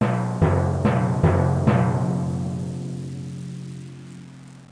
timpani1.mp3